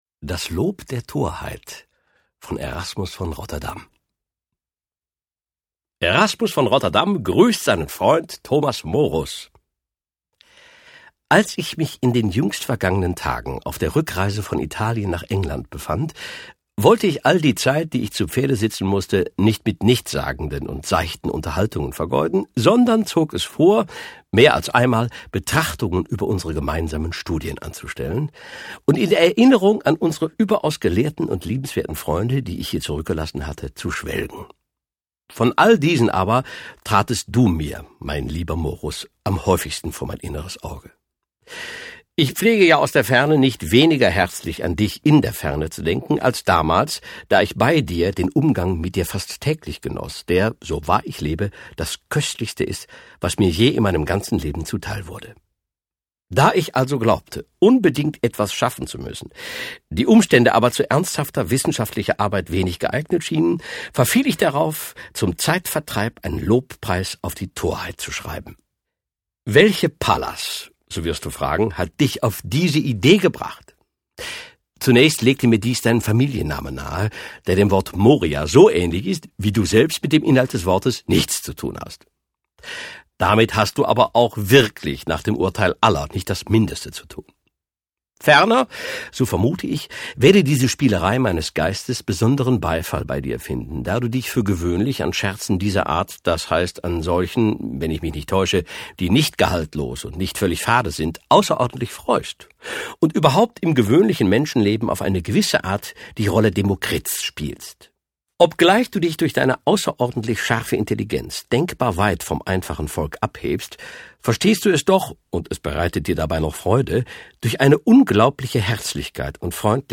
Jochen Malmsheimer (Sprecher)